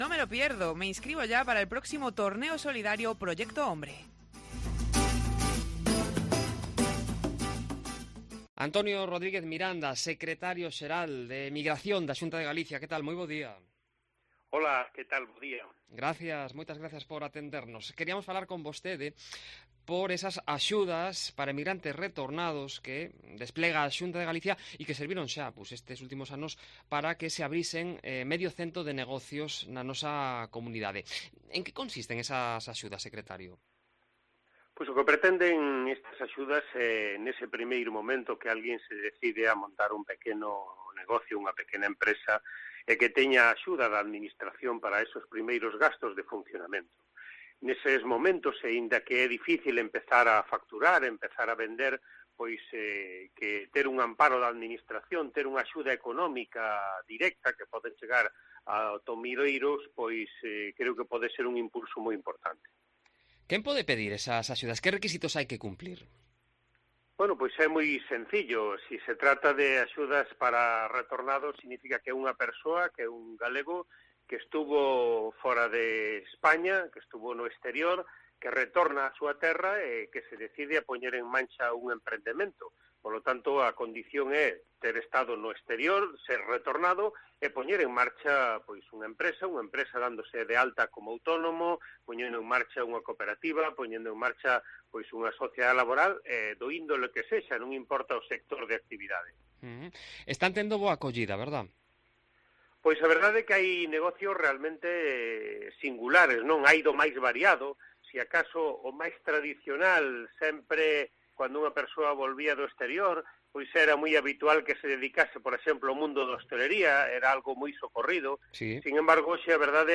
El secretario general de Emigración de la Xunta explica que son de ese país la mayoría de los que solicitan ayudas para montar un negocio en Galicia. También Brasil registra un repunte a causa de la delicada situación económica que se vive allí.